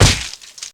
gore1.ogg